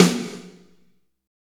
Index of /90_sSampleCDs/Roland - Rhythm Section/KIT_Drum Kits 1/KIT_R&R Kit 1
SNR PLATE04R.wav